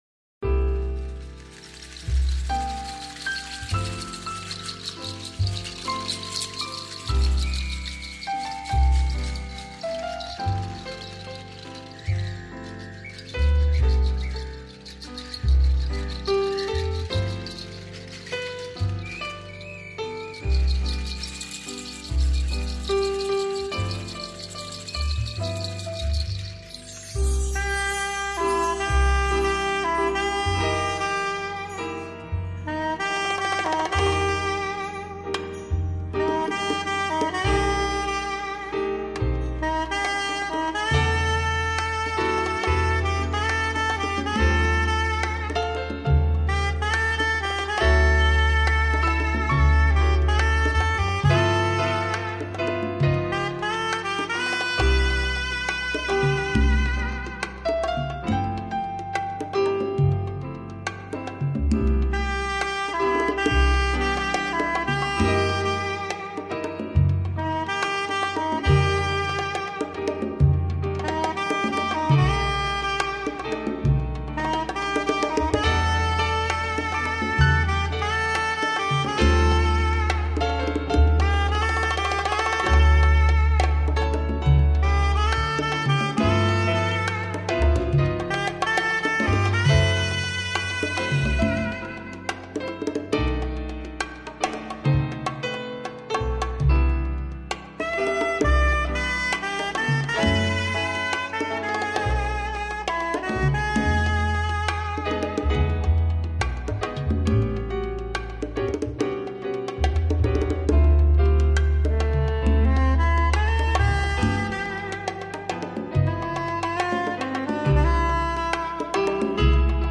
1319   05:28:00   Faixa:     Jazz